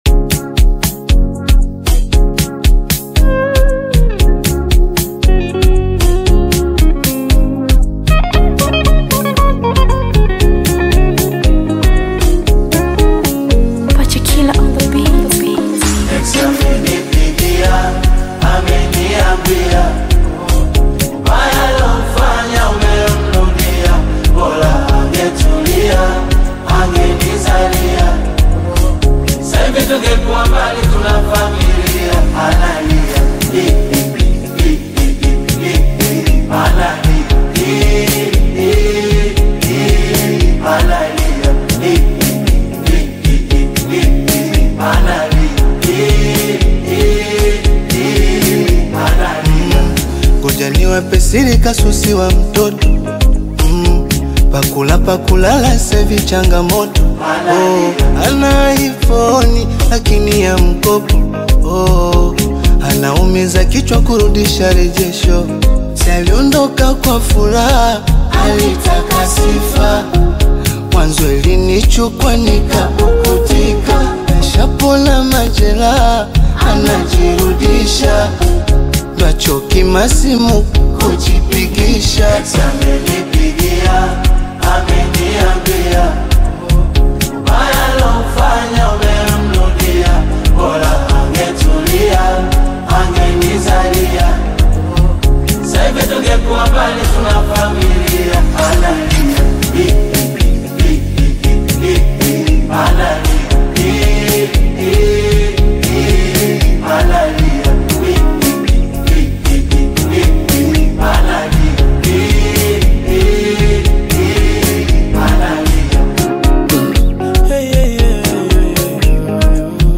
emotive, genre-blending music